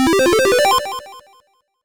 extra_life.wav